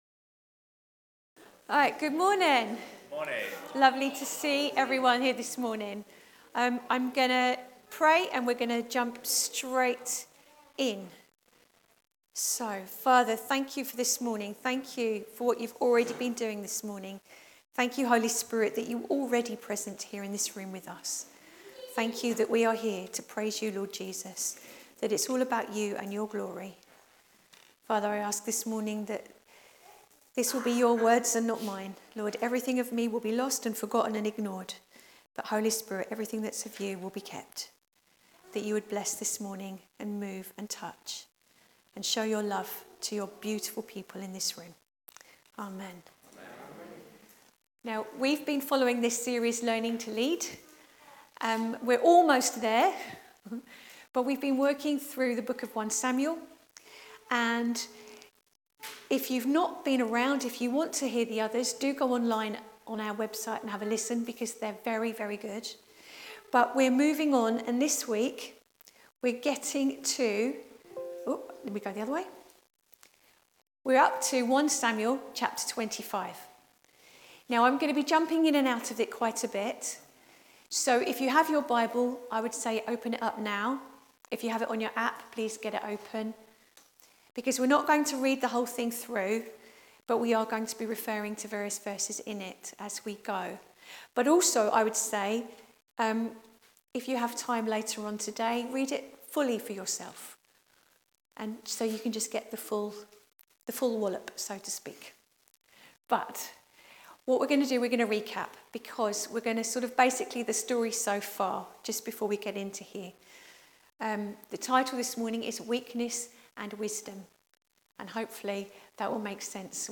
Here is a link to the video which was shown during the sermon.